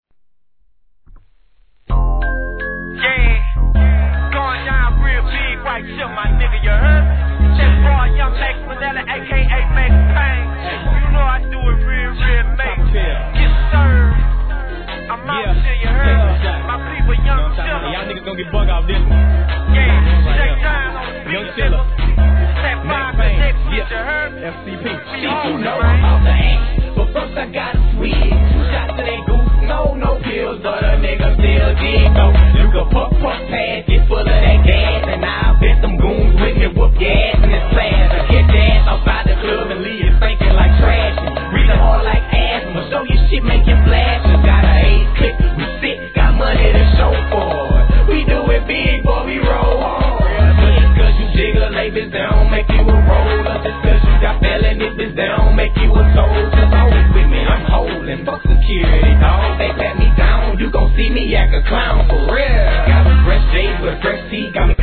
HIP HOP/R&B
SOUTHファン直球のビートに、歌うようなフロウと粘りっこいフロウのコンビ♪